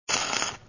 weld5.wav